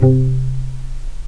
vc_pz-C3-pp.AIF